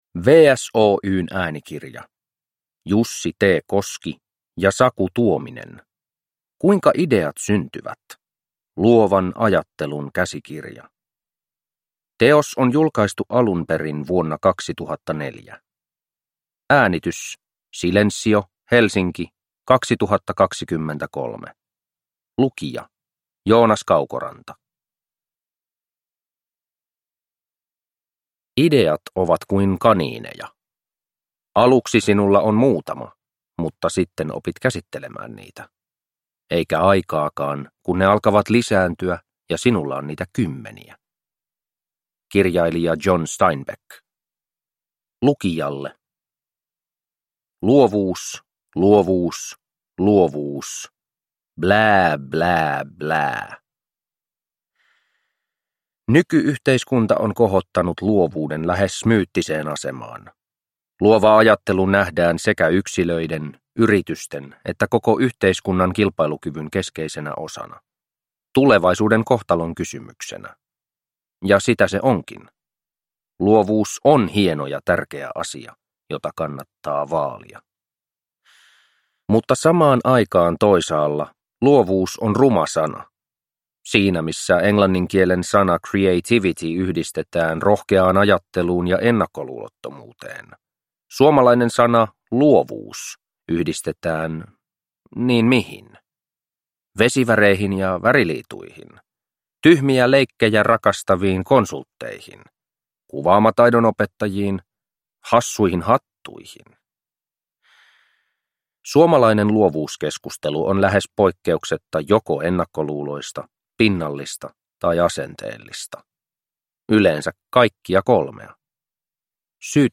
Kuinka ideat syntyvät? Luovan ajattelun käsikirja – Ljudbok – Laddas ner